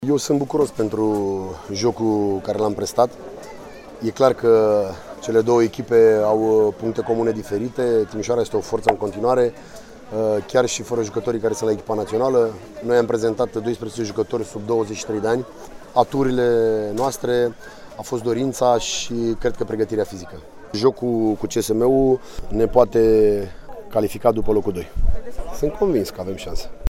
Iată şi declaraţiile antrenorilor